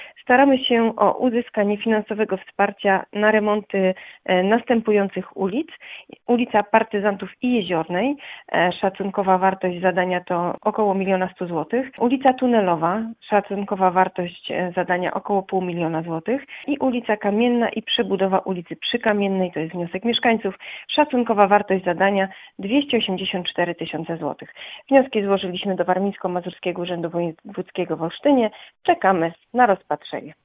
Jak poinformowała w Radiu 5 Sylwia Wieloch, zastępca burmistrza Olecka, wnioski o dofinansowanie remontu ulic Partyzantów, Jeziornej, Tunelowej, Kamiennej oraz przebudowy ulicy Przykamiennej zostały już złożone do Warmińsko-Mazurskiego Urzędu Wojewódzkiego w Olsztynie.